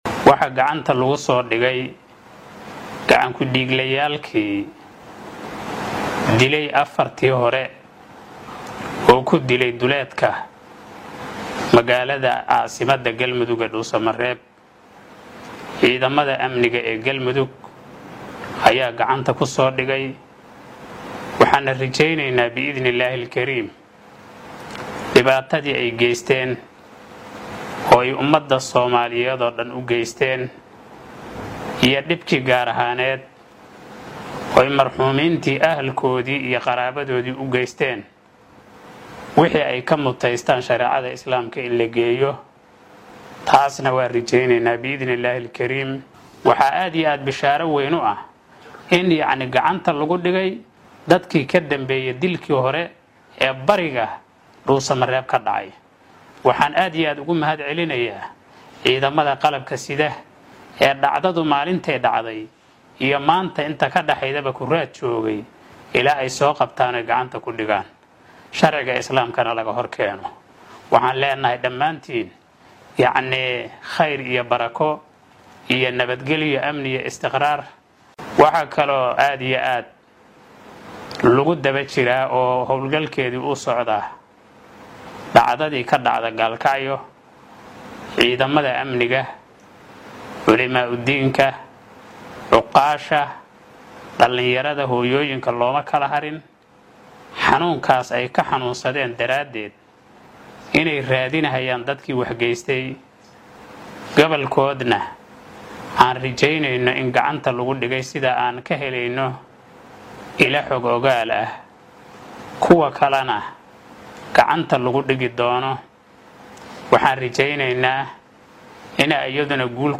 Dhuusomareeb (Caasimada Online)-Madaxa xukuumadda Galmudug Sheikh Maxamed Shaakir Cali oo saxaafadda kula hadlayay magaalada Dhuusomareeb ayaa shaaciyay in ciidamada amaanka Galmudug ay gacanta ku dhigeen Shaqsiyaad dad shacab ahaa ku dilay duleedka magaalada Dhuusomareeb.